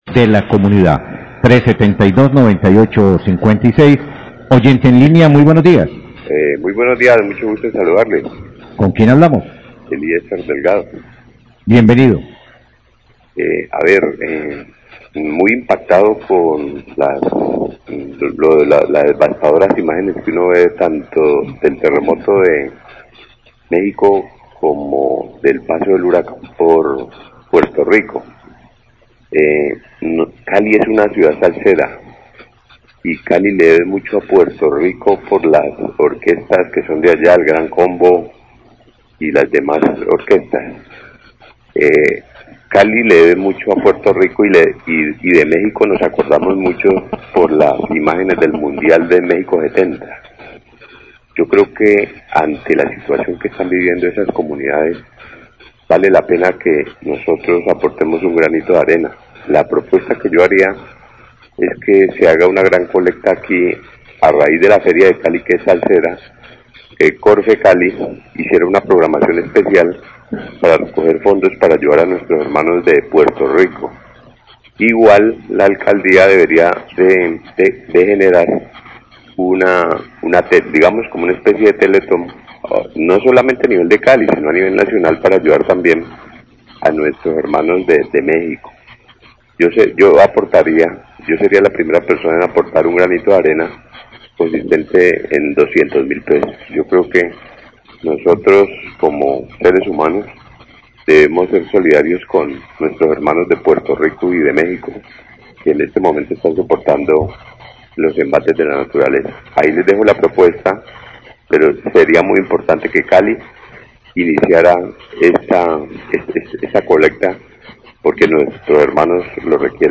Radio
Oyente